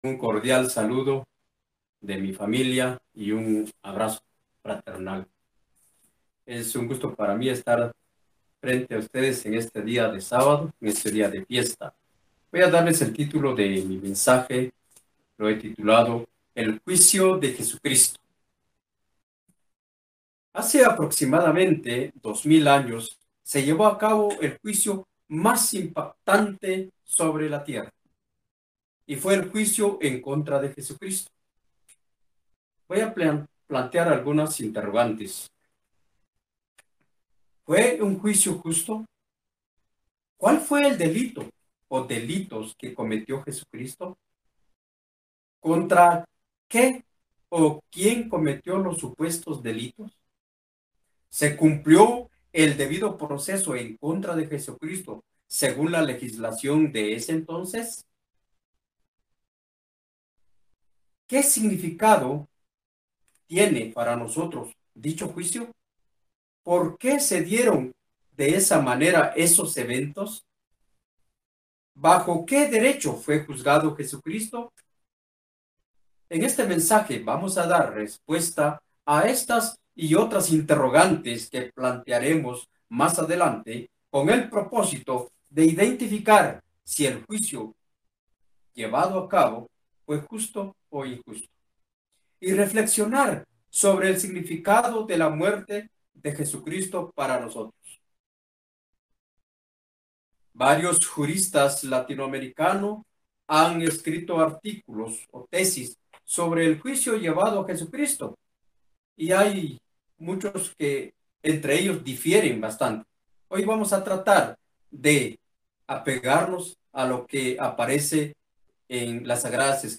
Es importante conocer algunos principios de la legislación judía para comprender cabalmente el turbulento proceso judicial que pasó Jesucristo y respondernos a la pregunta ¿por qué los eventos se dieron de esa manera?. Mensaje entregado el 30 de abril de 2022.